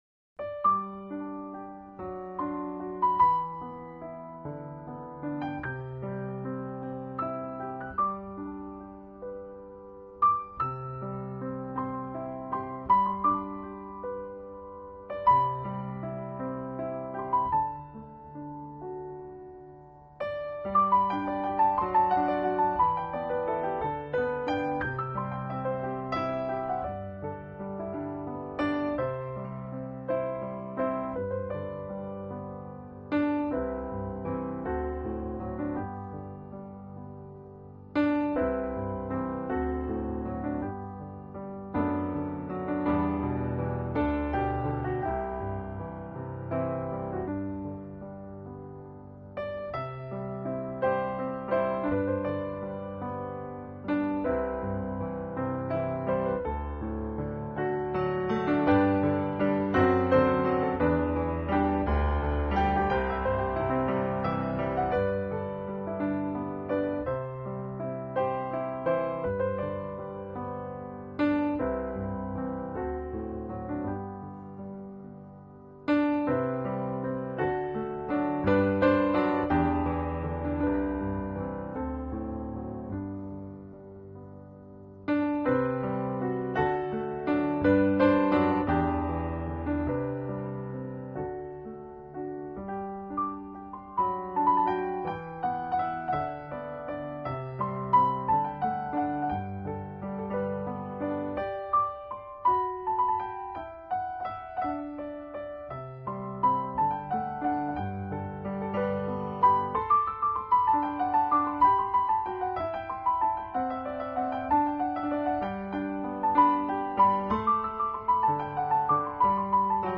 纯音钢琴